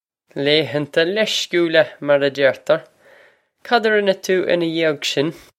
"Lay-han-ta lesh-kool-ah," marr ah jer-tur! Cod ah rin-eh too inna yee-og shin?
This is an approximate phonetic pronunciation of the phrase.